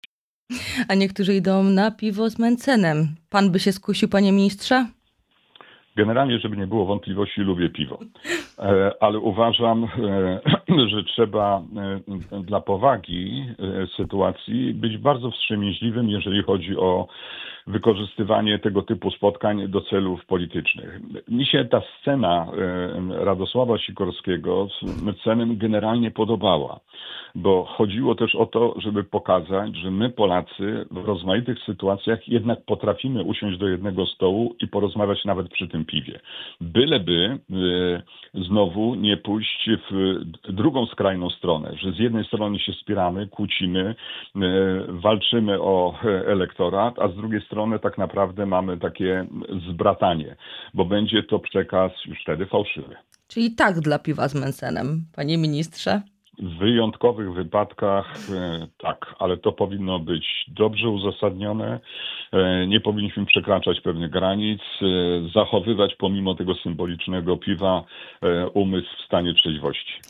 W rozmowie „Poranny Gość” z europosłem Bogdanem Zdrojewskim rozmawialiśmy o wizycie prezydenta Karola Nawrockiego w USA, relacjach z rządem, a także o zmianach i wyborach w PO.